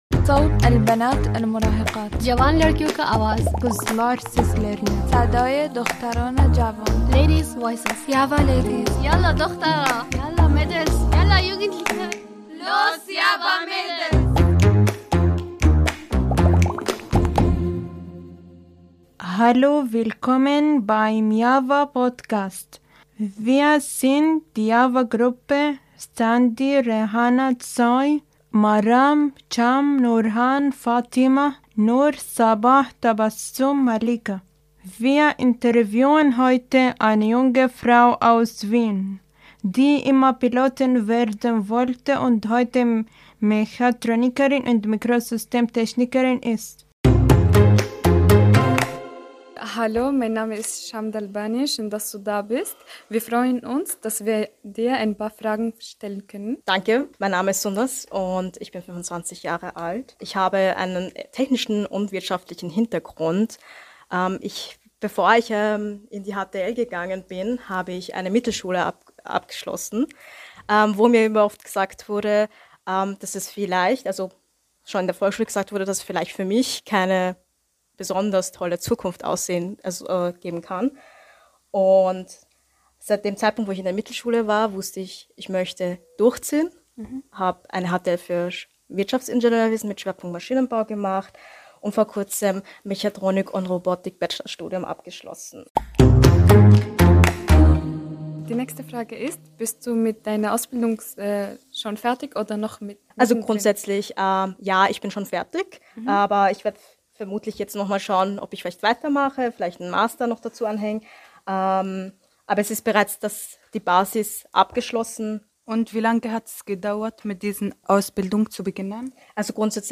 Im Podcast-Workshop haben wir mit jungen Frauen aus dem Projekt JAWA Next gearbeitet.
Junge Frauenstimmen – An die Mikros!